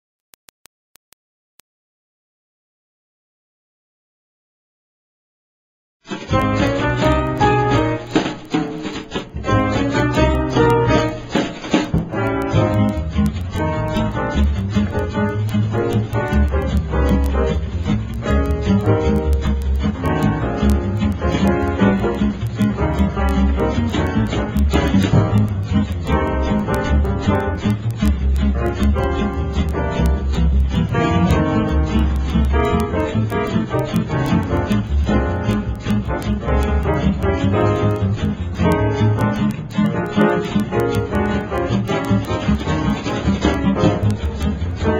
NOTE: Background Tracks 11 Thru 20